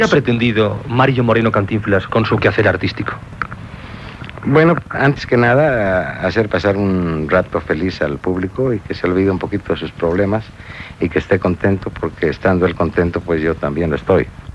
Entrevista a l'actor Mario Moreno "Cantinflas".
Extret de Crònica Sentimental de Ràdio Barcelona emesa el dia 29 d'octubre de 1994.